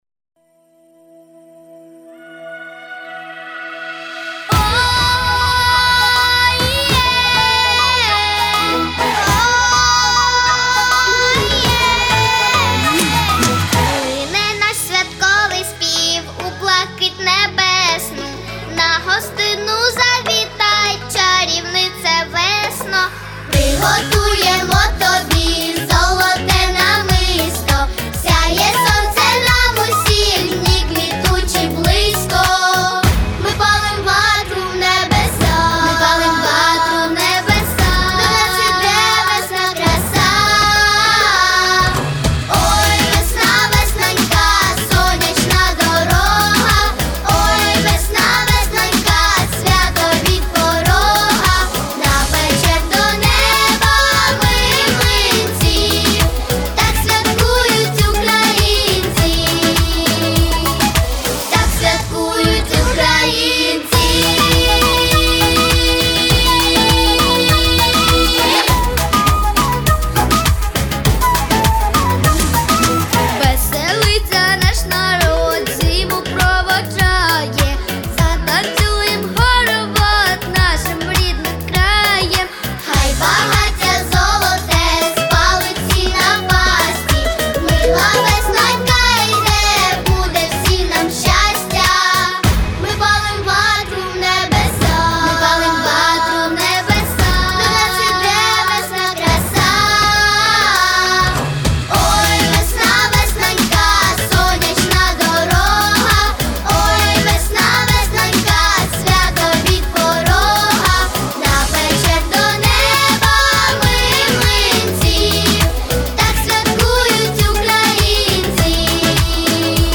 у виконанні молодшого дитячого колективу
Двоголосна партитура, тональність До-дієз мінор.